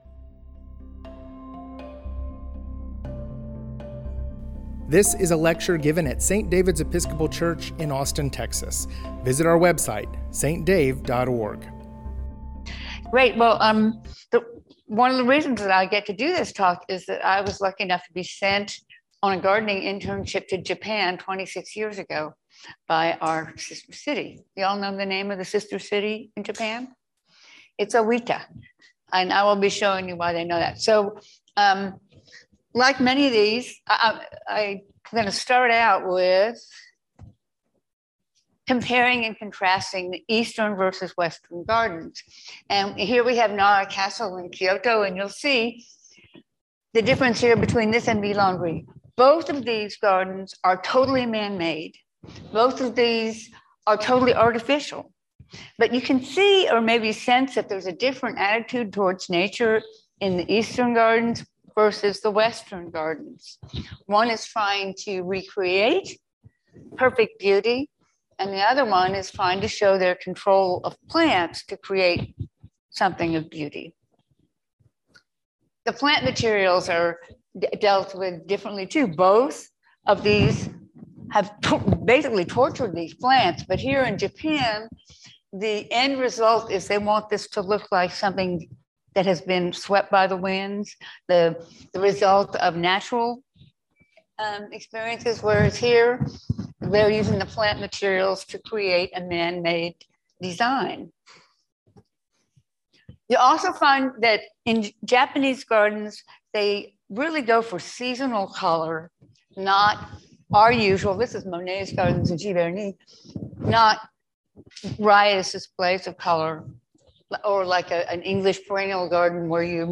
japanese-gardening-lecture.mp3